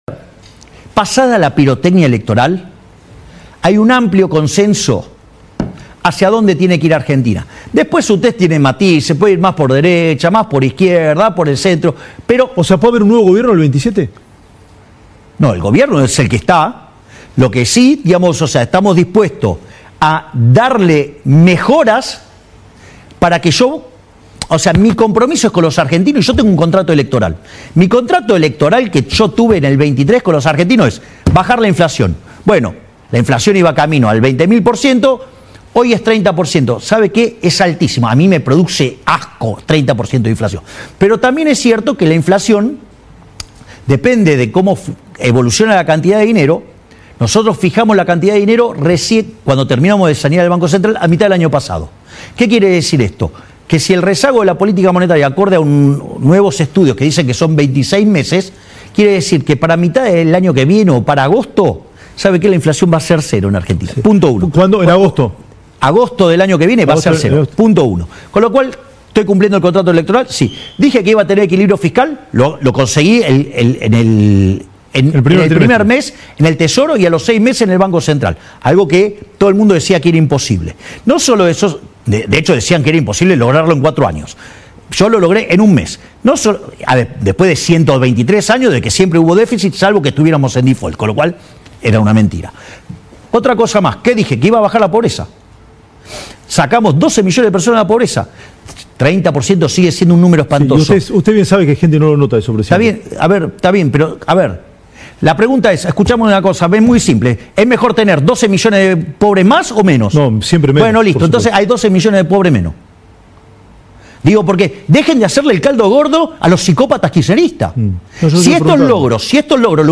“Muchos cuestionaron la frase sobre la evolución de la economía cuando dije que iba a subir como pedo de buzo. Me acuerdo muchos econochantas diciendo ‘esto va a ser una nueva gran depresión’, estaban esperando caídas del PBI del 15%. Hay otros que decían que estabilizábamos y se quedaba en un bajo nivel”, continuó en diálogo con LN+.